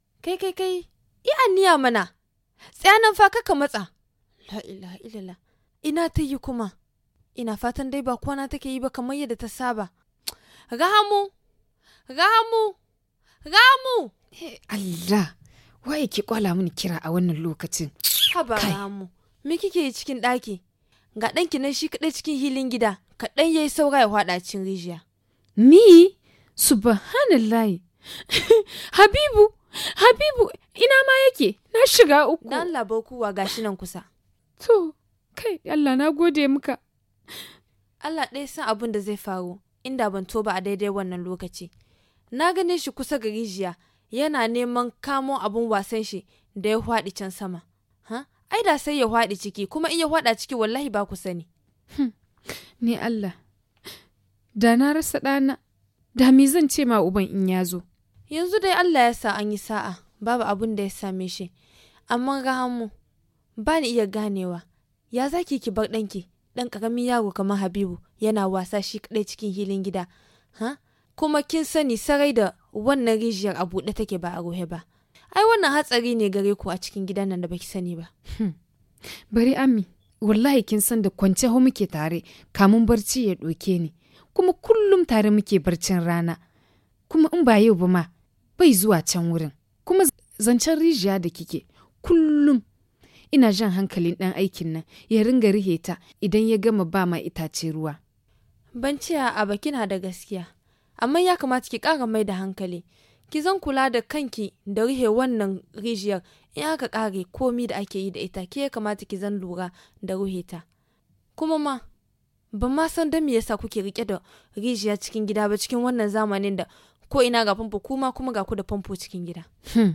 Suivons cette conservation entre ses deux amies.